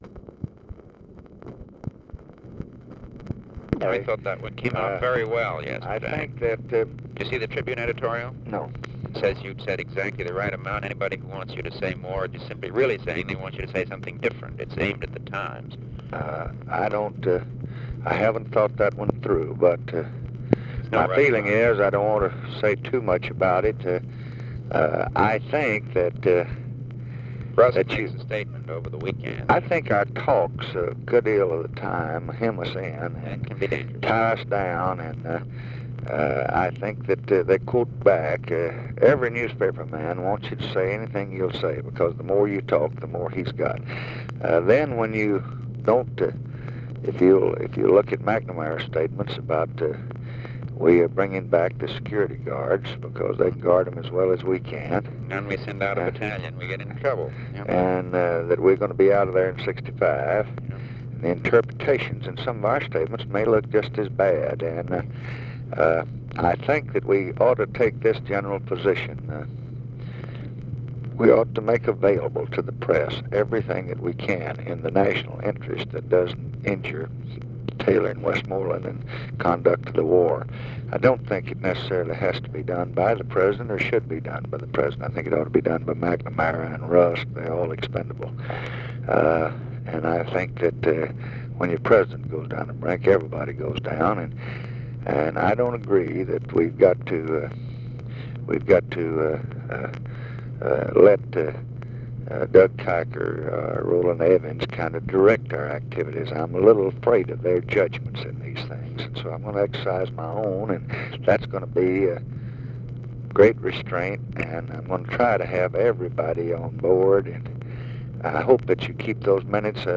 Conversation with MCGEORGE BUNDY, February 18, 1965
Secret White House Tapes